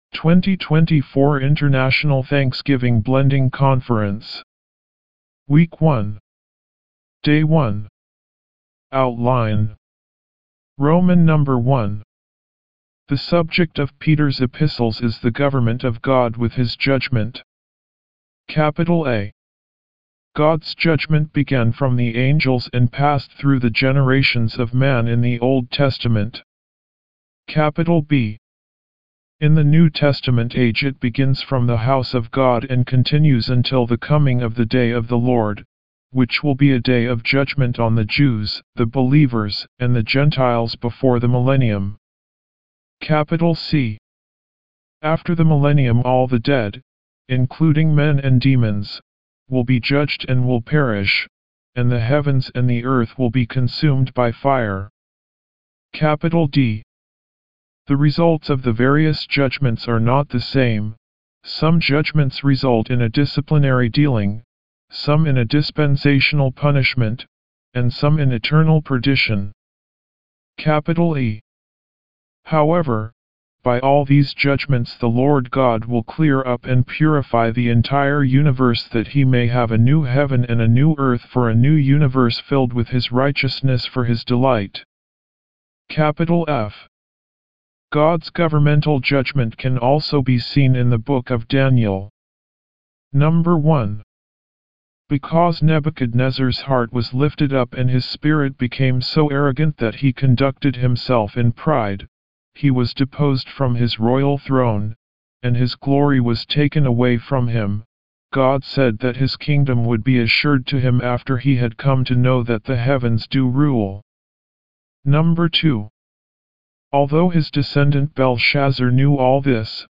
Morning Revival Recitation